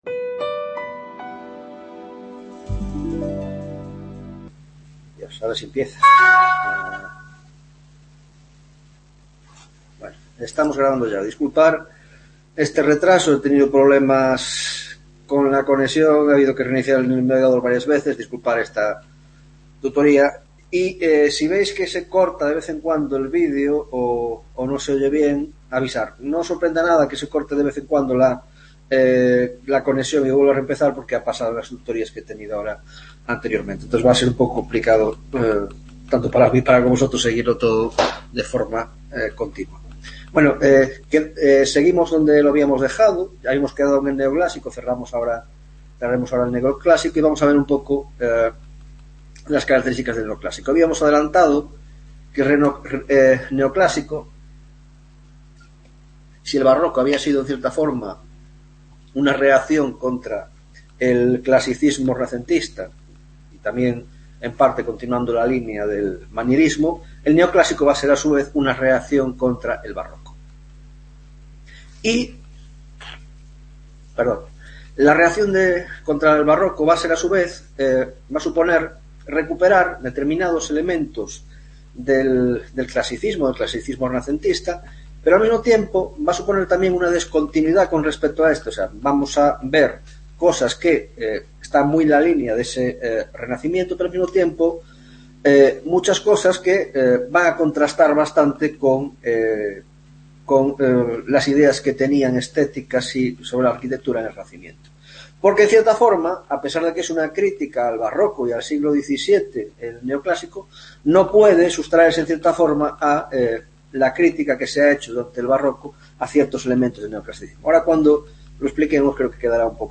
4ª Tutoria de Órdenes y Espacio en la Arquitectura Moderna - Teoria de los Ordenes: Legado Vitrubiano y Teoria de los órdenes en Italia (1ª parte)